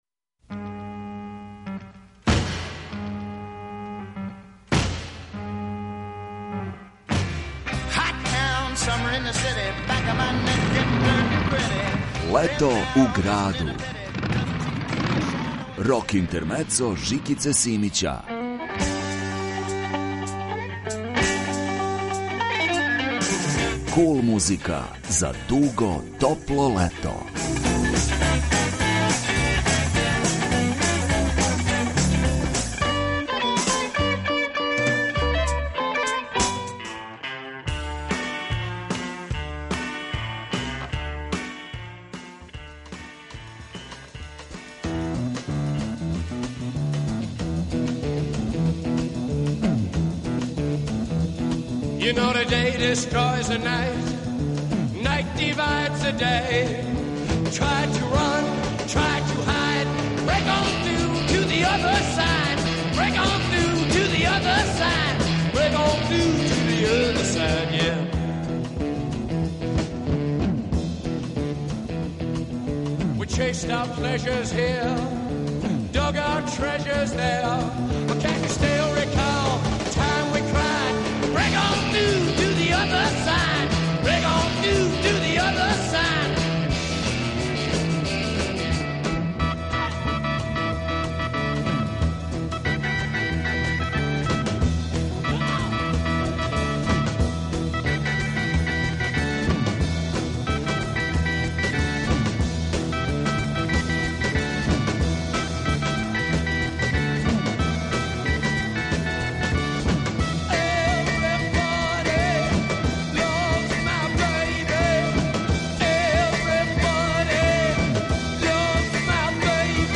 Kул музика за дуго топло лето.